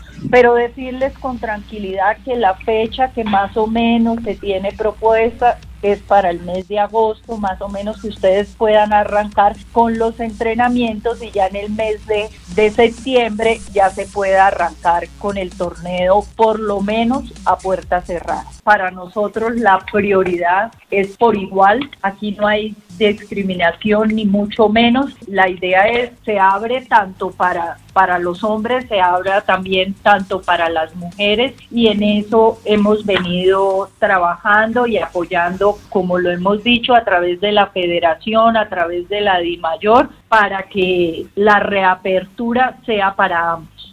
“La fecha que inicialmente se tiene propuesta es que en el mes de agosto se puedan arrancar los entrenamientos, ya en septiembre se podría dar inicio al torneo; eso sí, a puerta cerrada”, declaró la viceministra a UniValle Stereo.
Lina-Barrera-Viceministra-del-Deporte.mp3